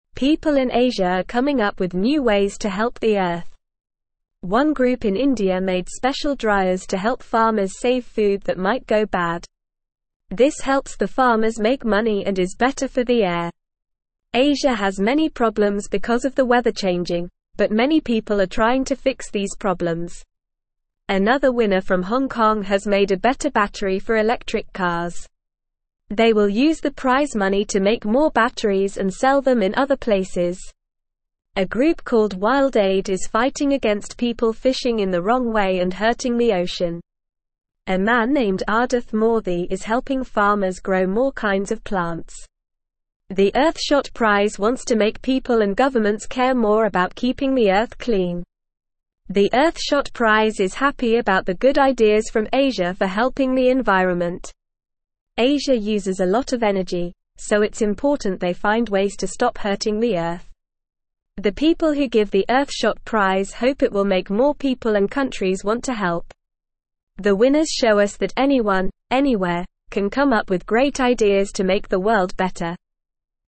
Normal
English-Newsroom-Lower-Intermediate-NORMAL-Reading-People-in-Asia-Helping-Fix-the-Earth.mp3